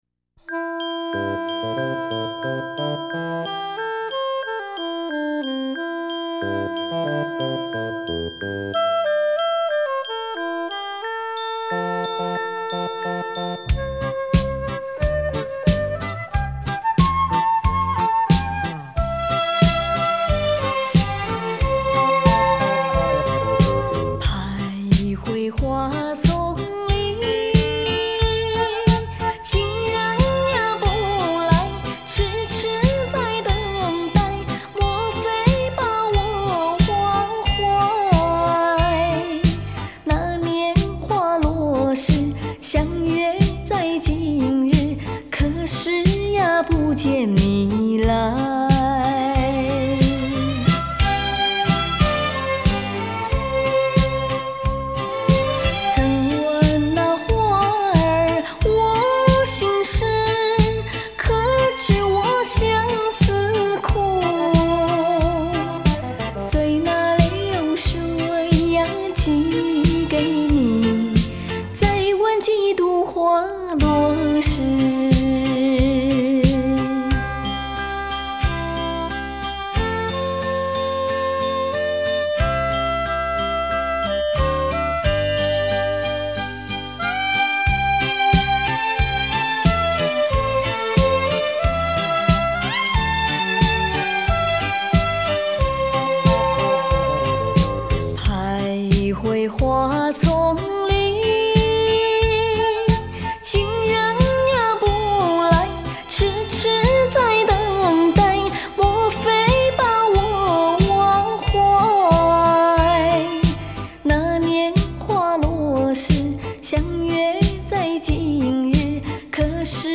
伴奏不错，歌曲也好听，猜猜谁唱的？